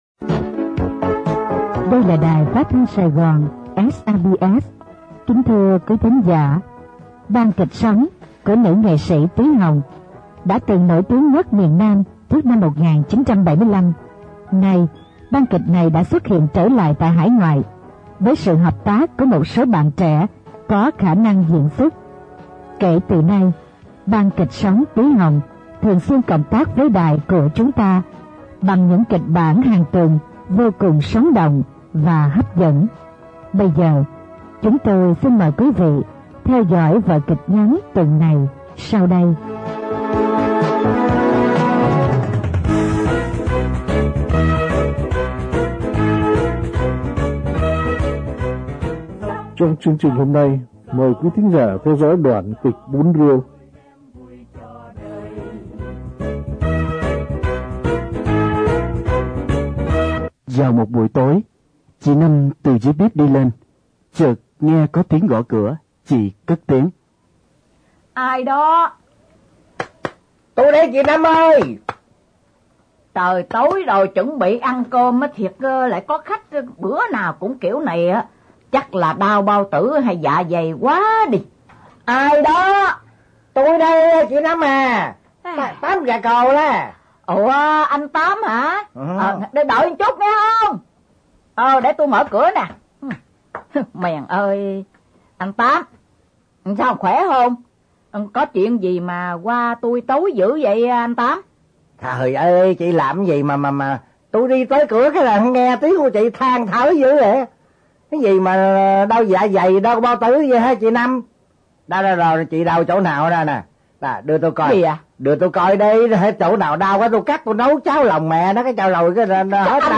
Kịch Nghệ